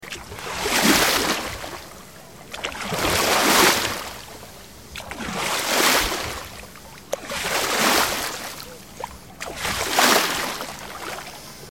دانلود آهنگ دریا 5 از افکت صوتی طبیعت و محیط
جلوه های صوتی
دانلود صدای دریا 5 از ساعد نیوز با لینک مستقیم و کیفیت بالا